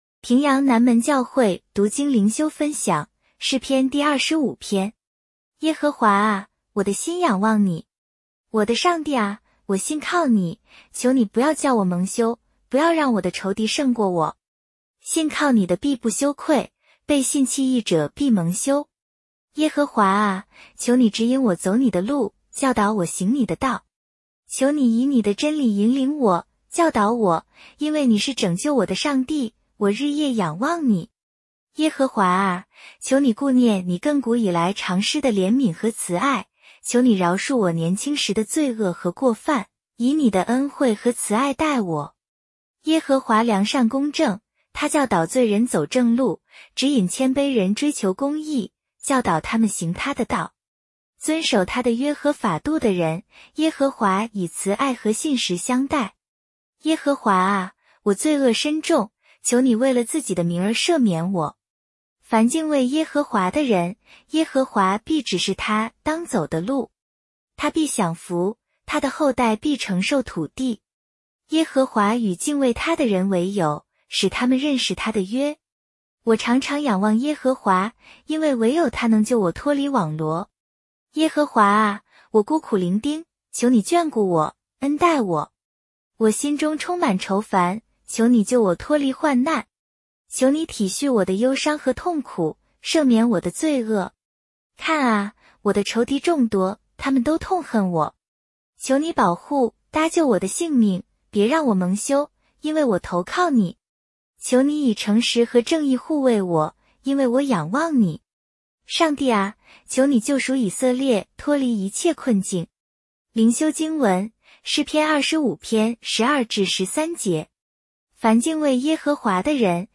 普通话朗读——诗25